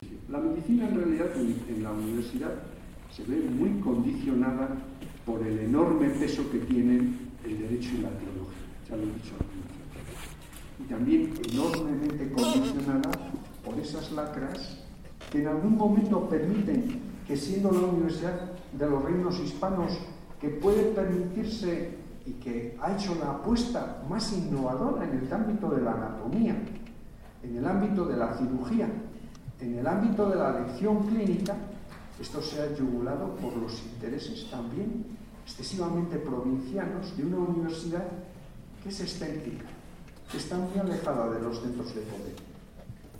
En su charla denominada “La Facultad de Medicina y la enseñanza de la medicina en Salamanca en la segunda mitad del siglo XVI”, resaltó que entonces la medicina se vio muy condicionada por el peso que tenían el derecho y la teología.